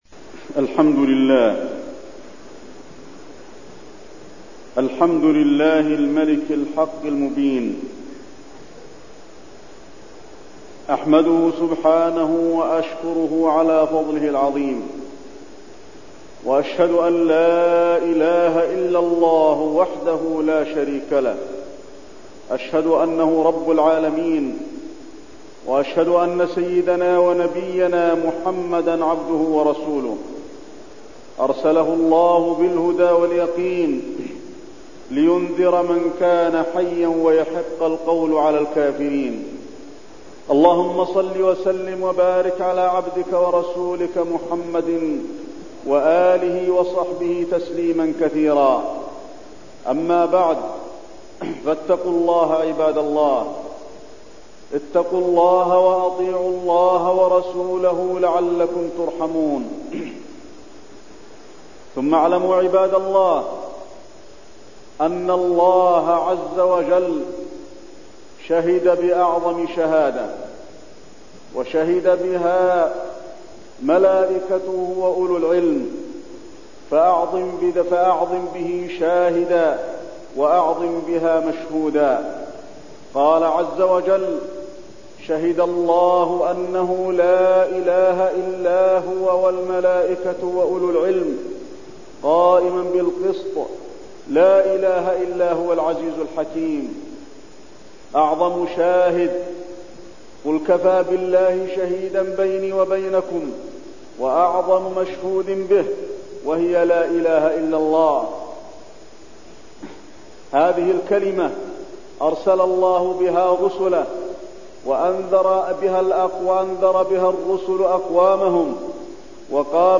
تاريخ النشر ١٦ صفر ١٤٠٨ هـ المكان: المسجد النبوي الشيخ: فضيلة الشيخ د. علي بن عبدالرحمن الحذيفي فضيلة الشيخ د. علي بن عبدالرحمن الحذيفي شهادة أن لا إله إلا الله